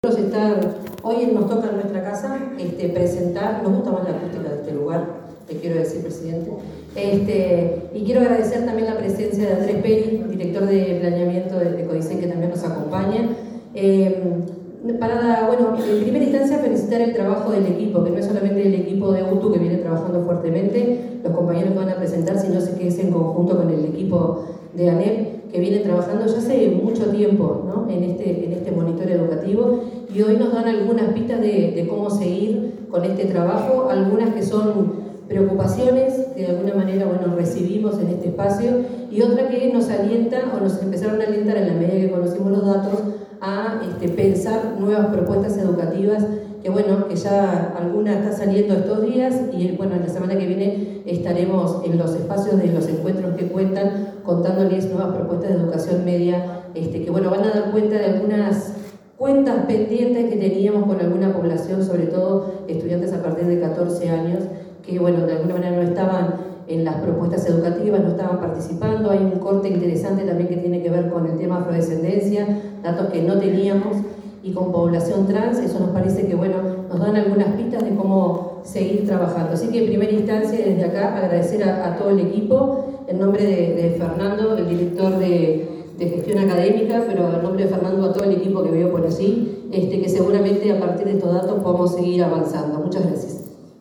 Palabras de la directora general de Educación Técnico Profesional, Virginia Verderese
La titular de la Dirección General de Educación Técnico Profesional (DGETP), Virginia Verderese, hizo uso de la palabra en la presentación del Monitor